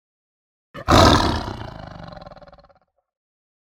Tiger Téléchargement d'Effet Sonore
Tiger Bouton sonore